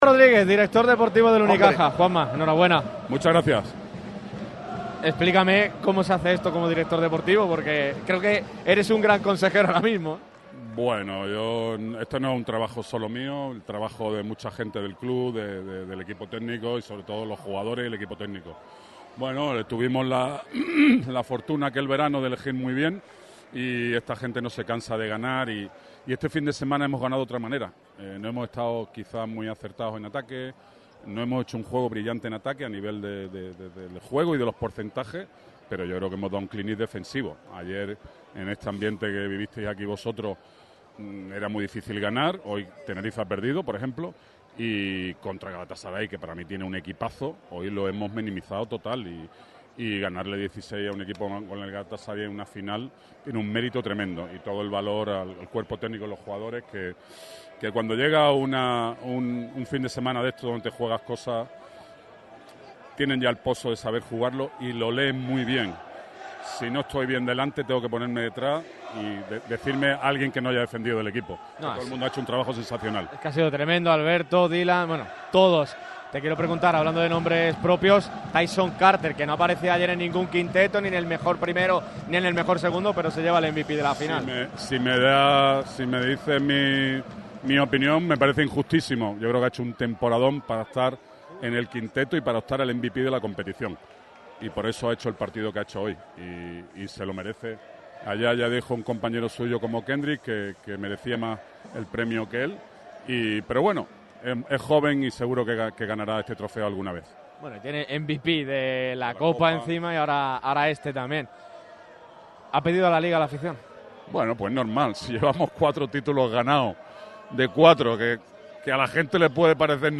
Las reacciones de los campeones de la BCL sobre el parqué del Sunel Arena.